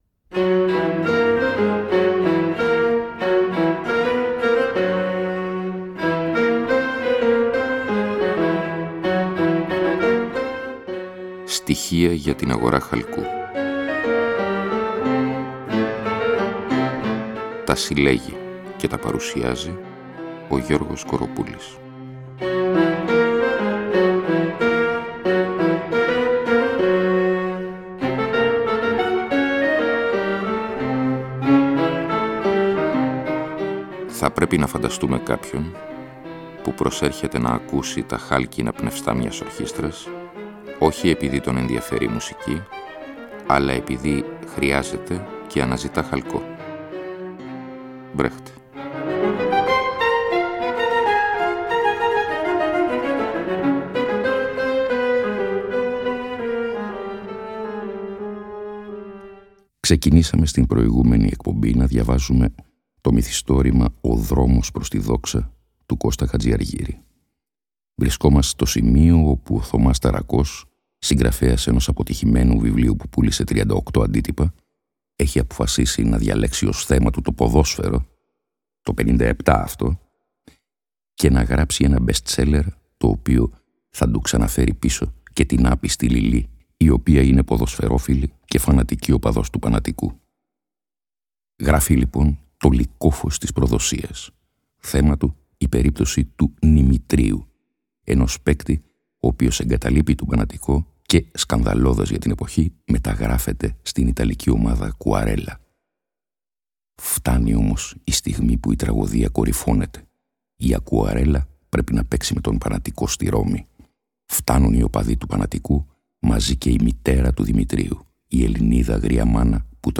Με αφορμή αφενός τα πρόσφατα κωμικολυρικά γεγονότα σχετικά με την Ακαδημία, αφετέρου την έναρξη του πιο άσπιλου Μουντιάλ, διαβάζονται και σχολιάζονται σελίδες από το σατιρικό μυθιστόρημα του προγραμματικά αγνοημένου Κώστα Χατζηαργύρη “Ο δρόμος προς τη δόξα” (1957).